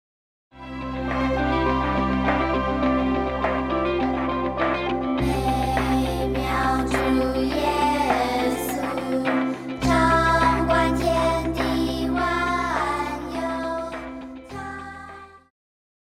Christian
Pop chorus,Children Voice
Band
Hymn,POP,Christian Music
Voice with accompaniment
為了淺顯易懂，除了把現代樂風融入傳統聖詩旋律，針對部份艱澀難懂的歌詞，也稍作修飾，儘可能現代化、口語化；